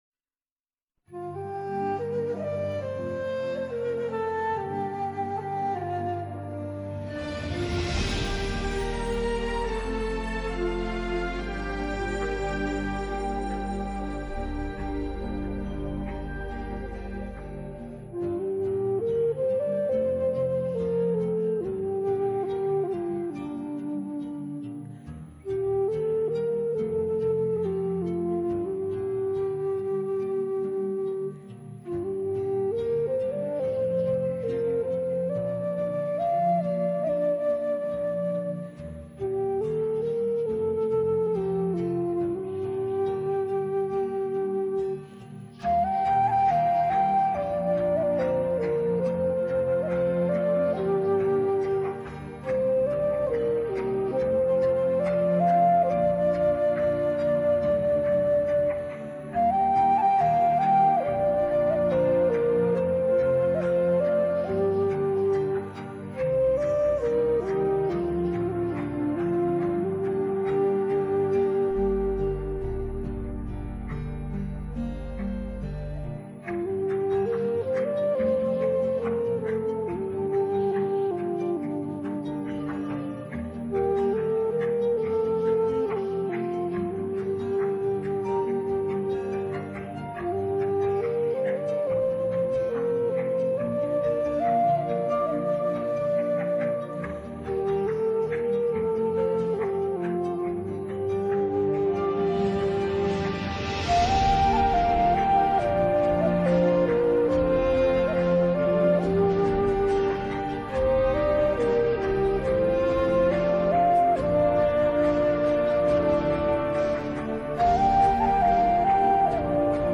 洞箫很独特的音乐风格
洞箫声音低沉醇厚、空灵深邃，如泣如诉，能营造出宁静、孤寂或悠远的氛围，直达人心。